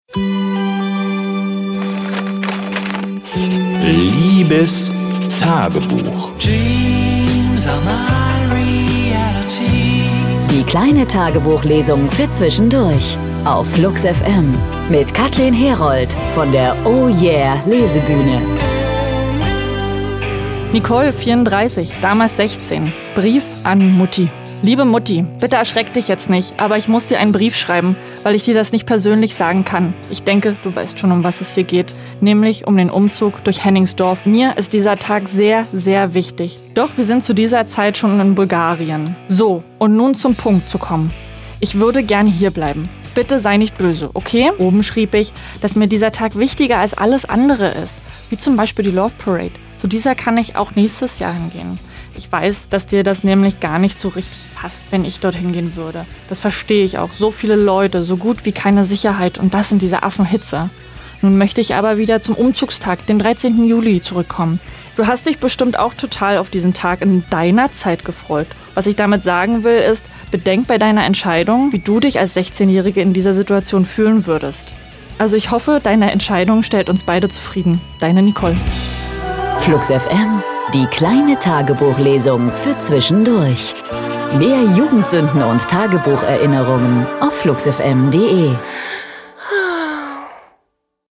Die kleine Tagebuchlesung für Zwischendurch